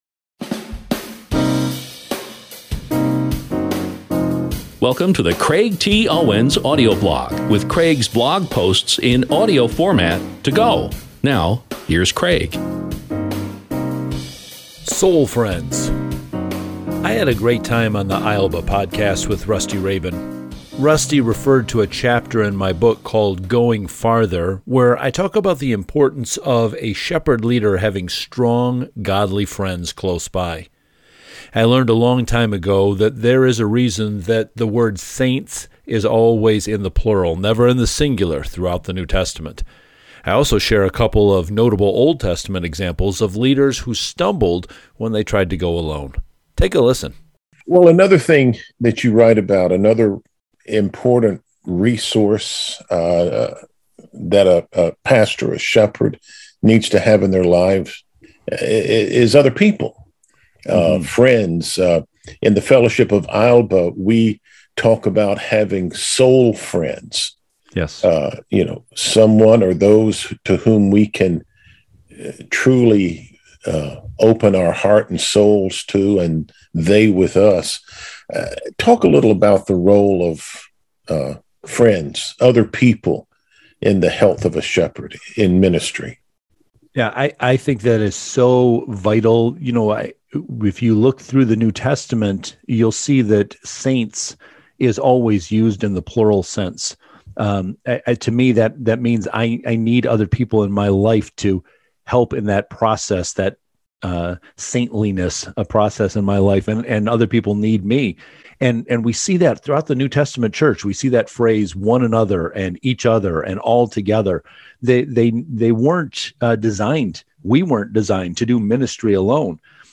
I’ll be sharing more clips from this interview soon, so please stay tuned.